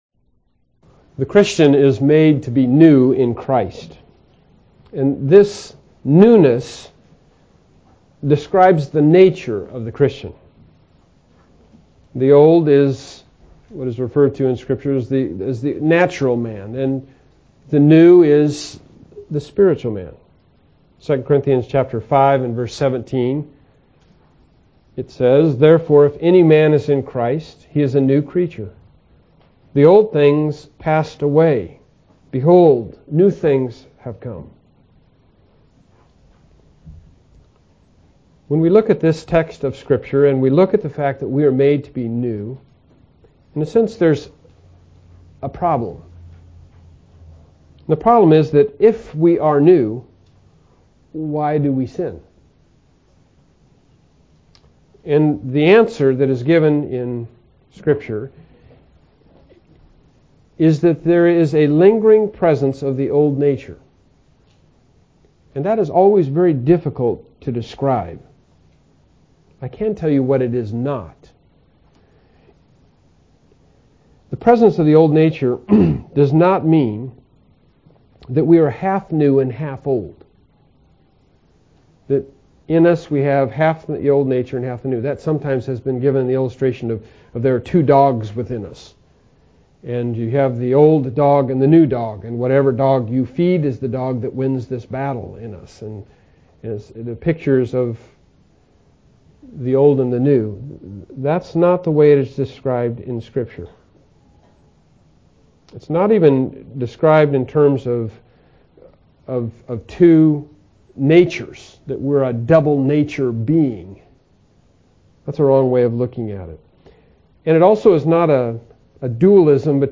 Bible Text: Romans 6:13b-14 | Preacher